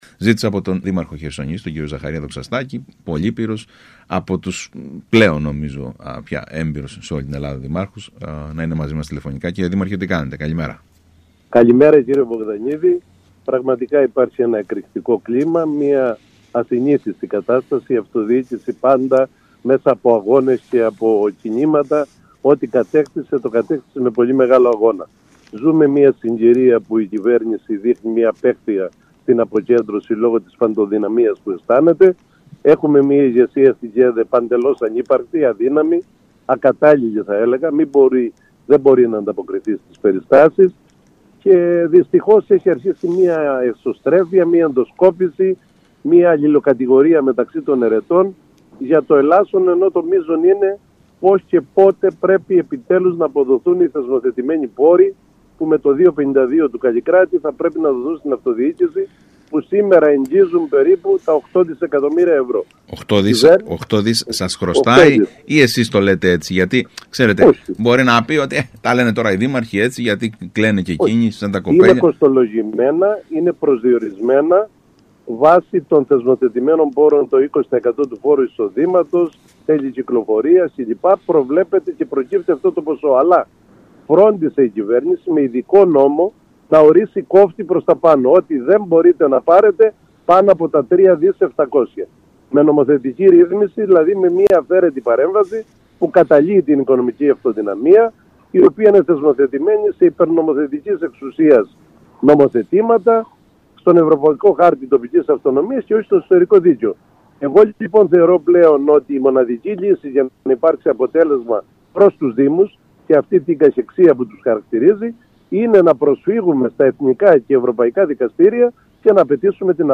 Την προσφυγή των δήμων Χερσονήσου και Πλατανιά από κοινού κατά της ελληνικής κυβέρνησης για τη μη απόδοση των κονδυλίων από το τέλος ανθεκτικότητας προανήγγειλε μιλώντας στον ΣΚΑΙ Κρήτης ο Ζαχαρίας Δοξαστάκης λέγοντας χαρακτηριστικά ότι «δεν πάει άλλο»!